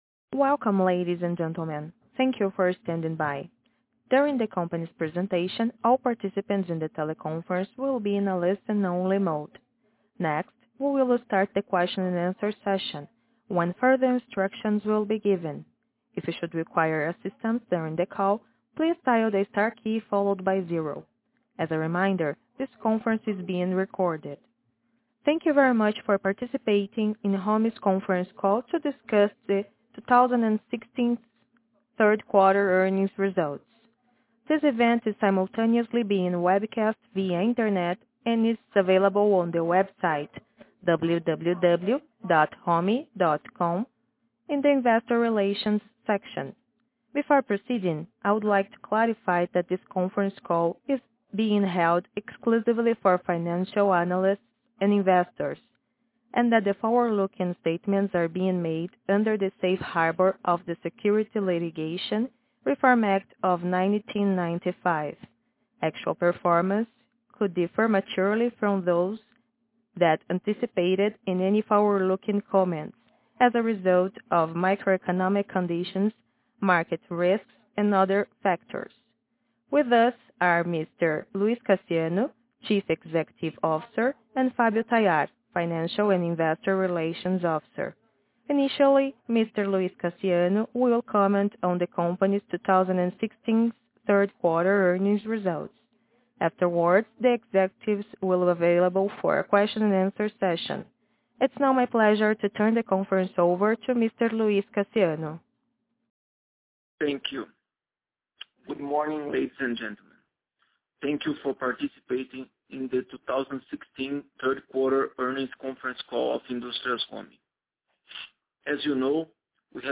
Audio from Quarter Teleconference